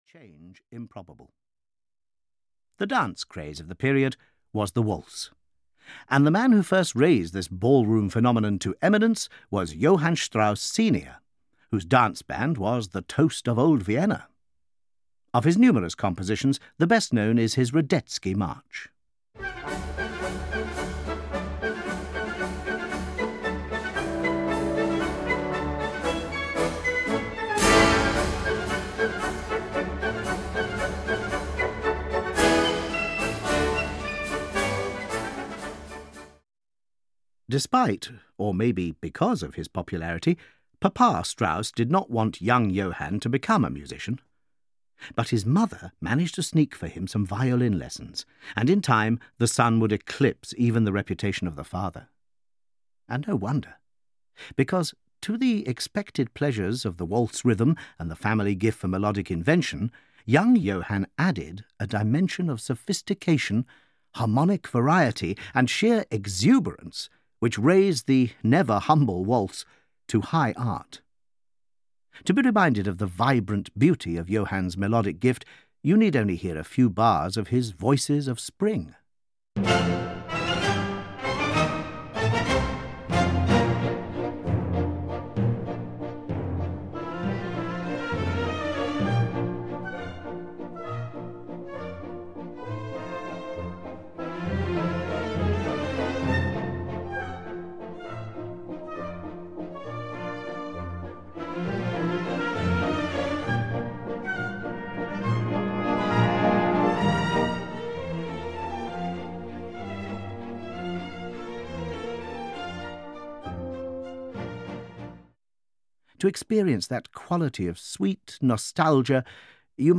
Opera Explained – Die Fledermaus (EN) audiokniha
The most rewarding and memorable extracts are included, and the whole piece is tantalisingly introduced – a trip to see it has to be the next step!